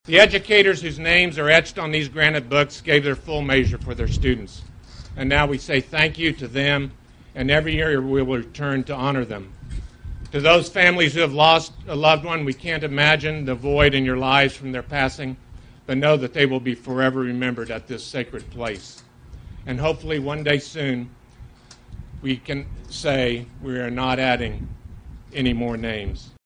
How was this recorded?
Well over 100 people gathered at the National Memorial to Fallen Educators for Friday’s rededication as part of the National Teachers Hall of Fame induction activities.